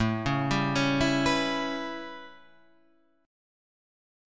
A9 chord {5 4 5 6 5 7} chord